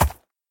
mob / horse / soft1.ogg
should be correct audio levels.